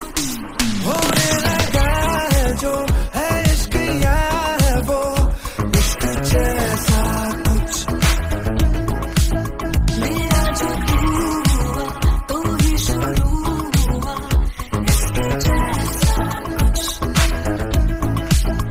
guitars and bass guitar